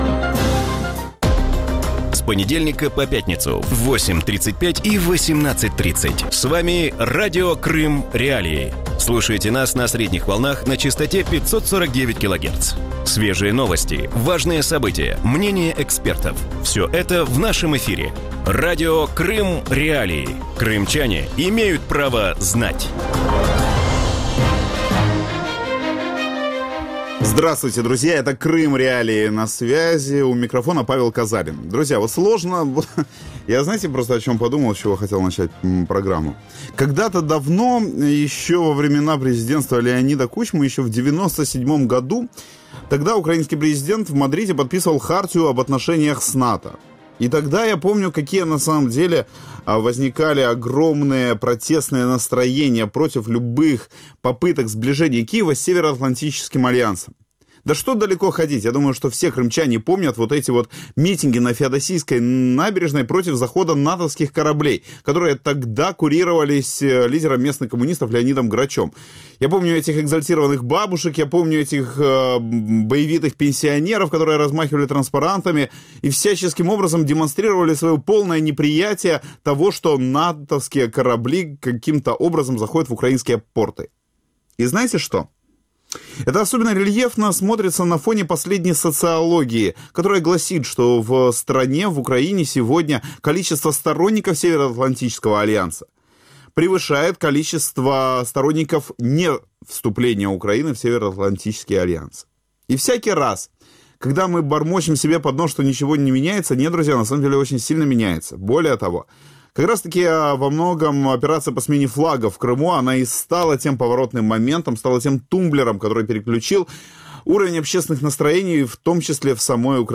В вечернем эфире Радио Крым.Реалии обсуждают итоги саммита НАТО в Варшаве. Как изменилось отношение альянса к России, какого формата сотрудничества стоит ожидать Украине и как саммит НАТО отразится на Крыме?